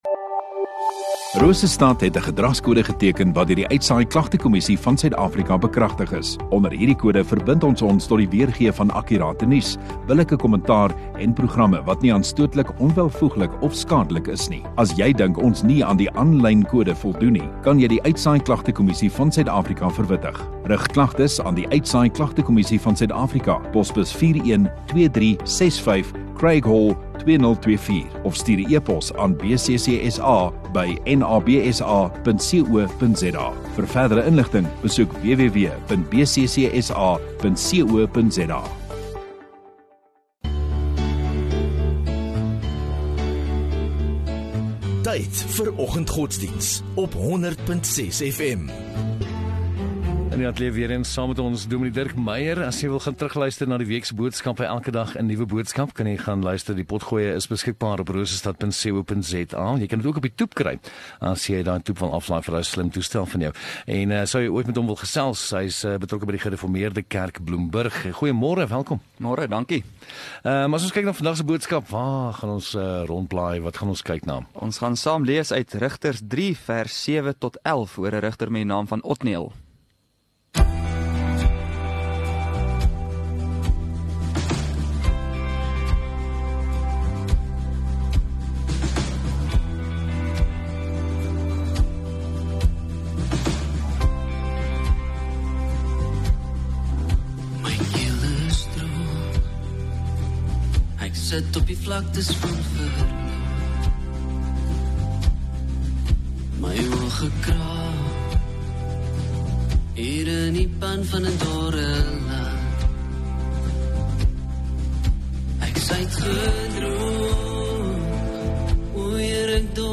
2 May Donderdag Oggenddiens